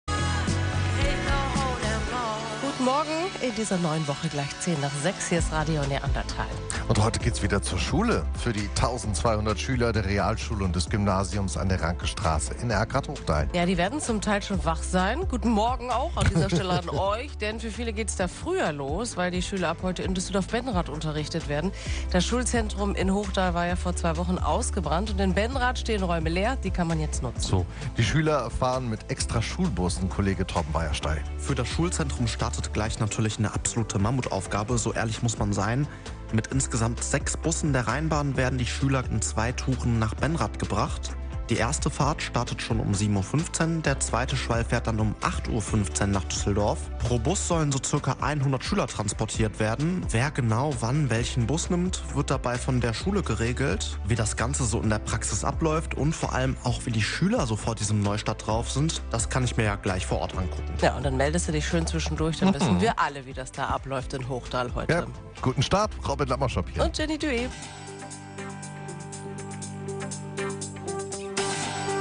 Wir waren heute live für Euch dabei!